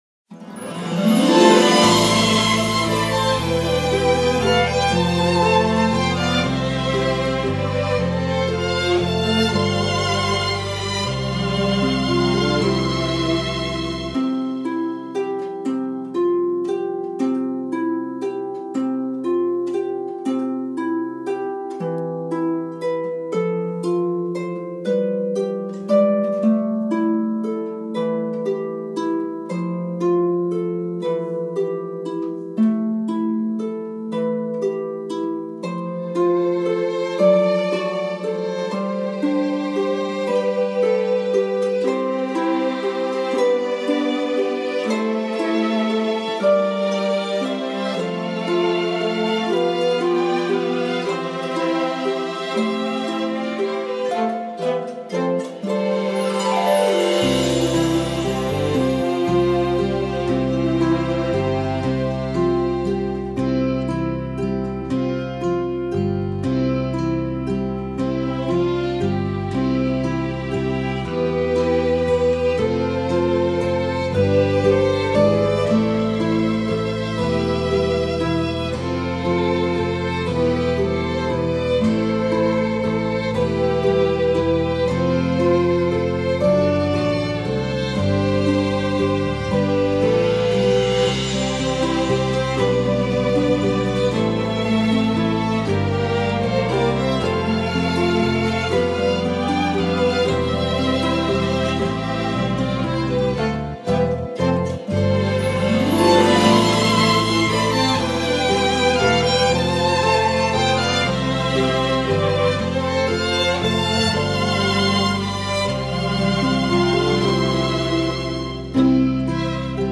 音源（カラオケ版）
ピアノ伴奏